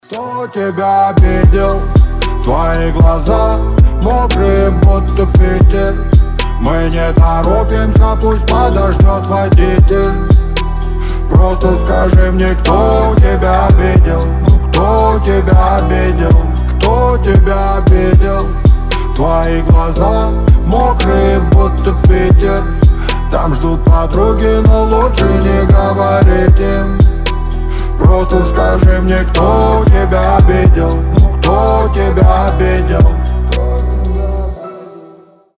• Качество: 320, Stereo
мужской голос
лирика
спокойные
медленные